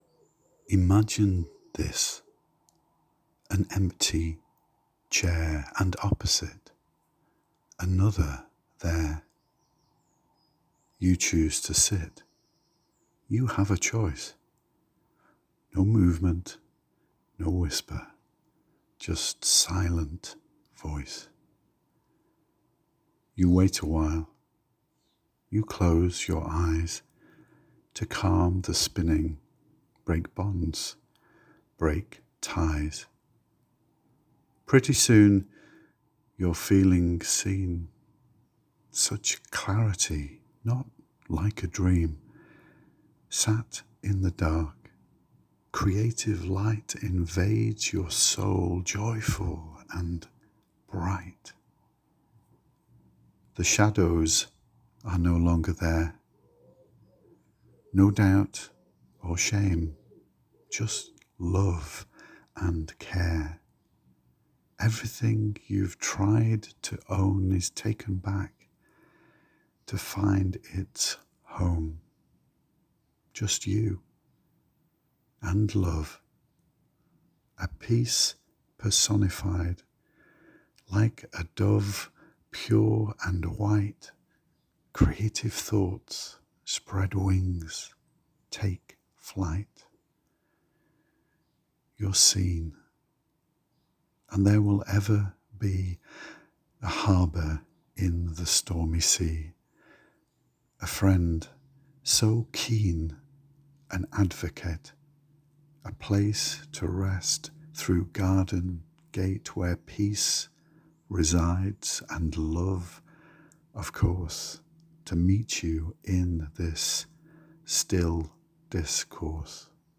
You have a soothing voice.